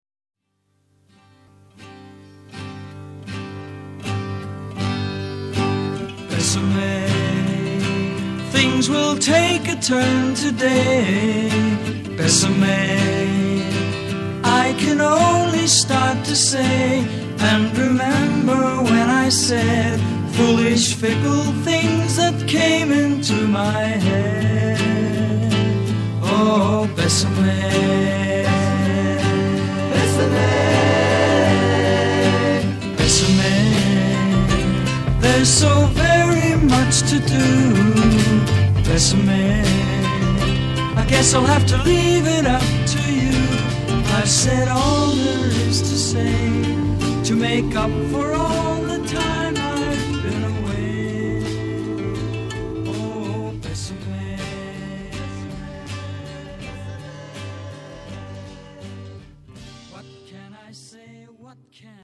blues-rock band our country has ever known